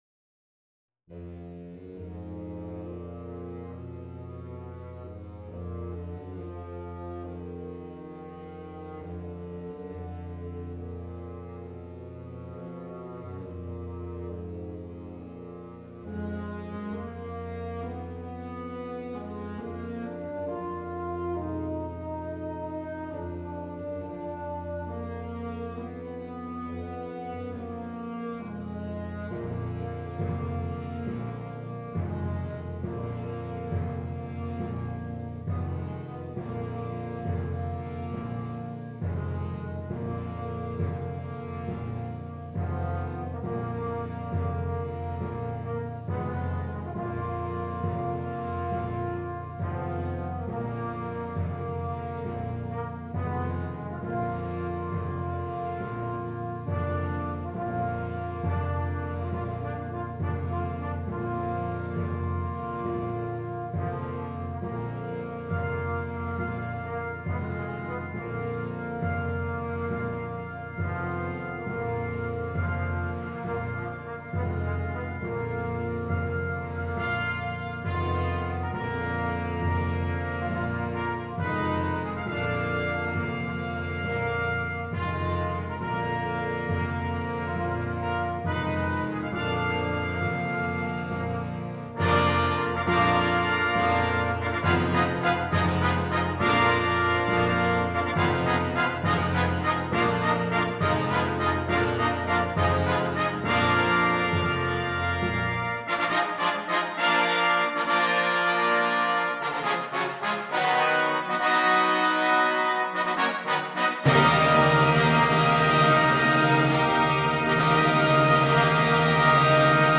Brano da concerto
Un grande brano descrittivo che ci porta nell’antica Roma.
MUSICA PER BANDA